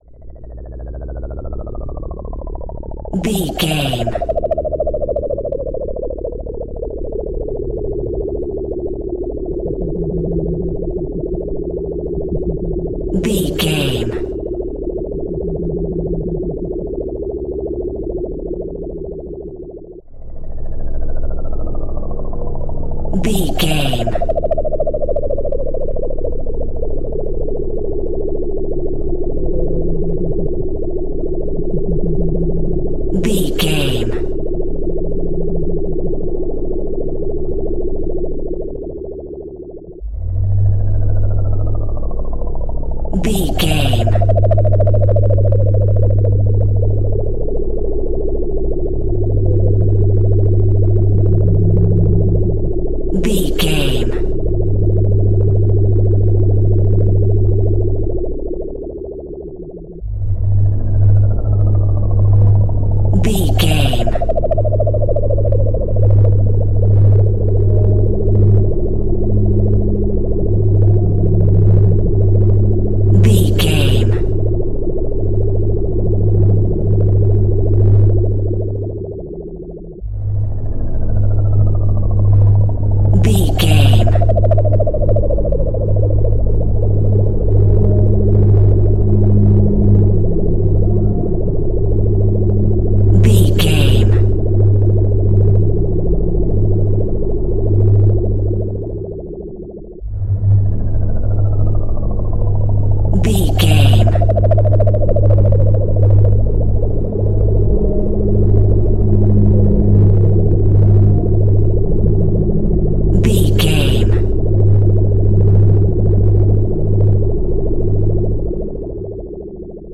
In-crescendo
Atonal
Slow
ominous
dark
eerie
synthesiser
strings
atmospheres